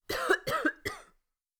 cough2.wav